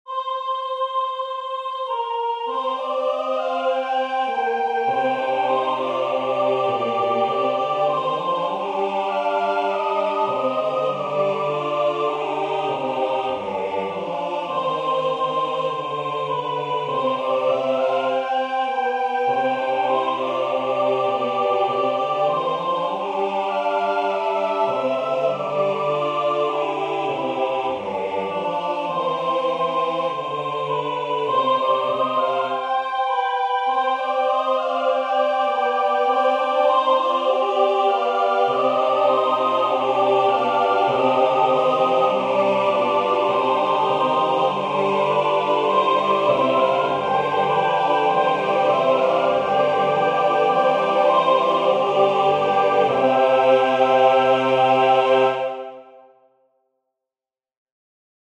Canons
The entire piece is based on a single tune. It is in three sections: two three-part canons and a six-part canon.
• The tune uses six of the seven scale degrees.
synthesized audio